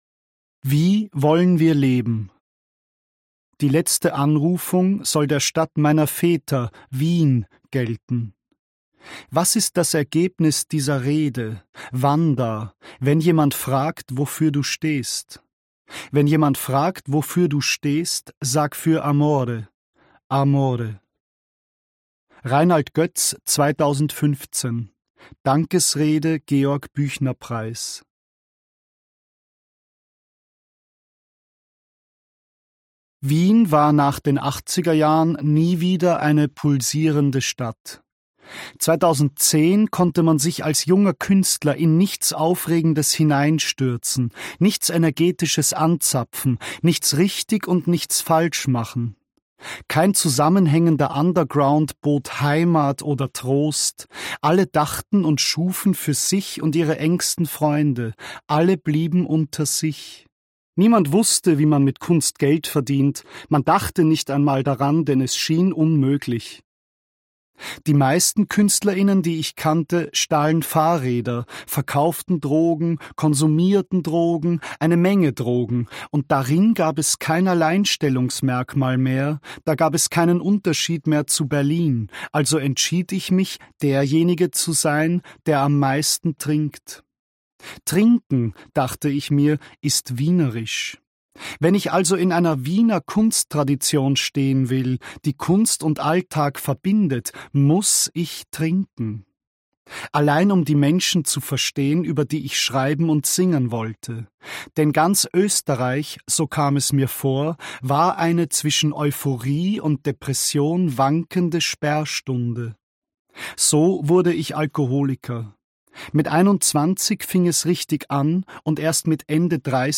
Marco Wanda (Sprecher)
Der Bandleader und Songwriter von Wanda hat ein Buch geschrieben und es selbst als Hörbuch eingelesen.